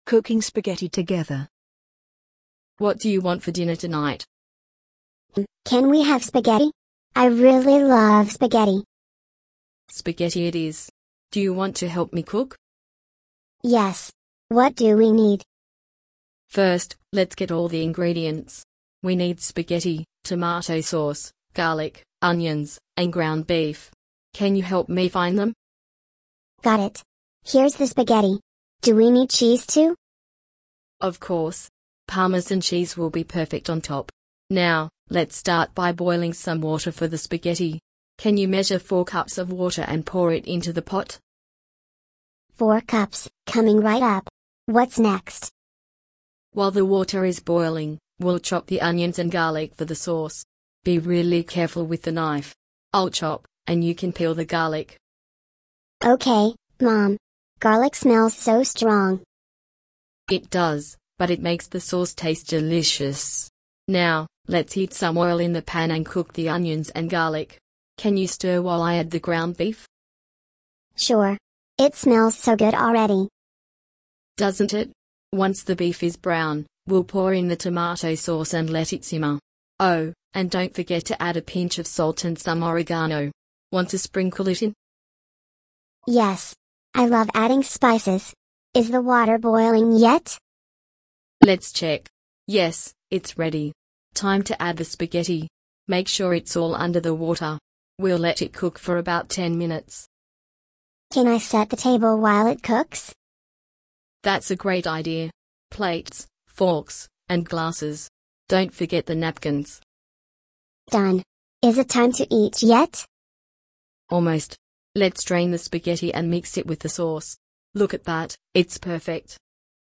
This lesson teaches a conversation between a mom and child while cooking spaghetti. Learners will practice vocabulary and phrases related to food, cooking, and kitchen tasks, helping them feel more confident discussing meals in English.